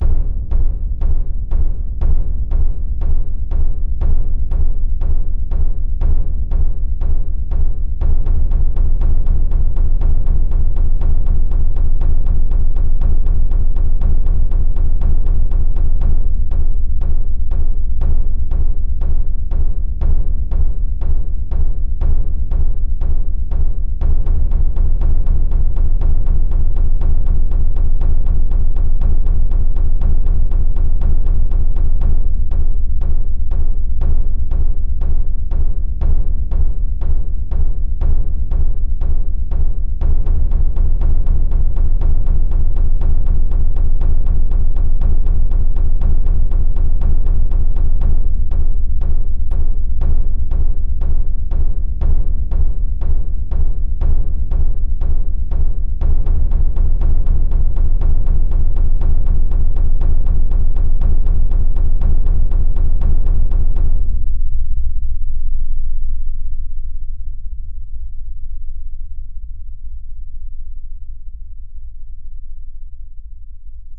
描述：奇怪的打击乐介绍。
100bpm。
最后需要淡出。
悬念 爬行 戏剧 电影 氛围 焦虑
神秘 紧张 电影 SF 怪异 视频 音乐 奇怪 器乐 木琴 电影 合成 刺激 吓人
声道立体声